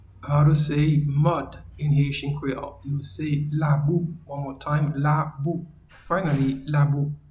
Pronunciation and Transcript:
Mud-in-Haitian-Creole-Labou.mp3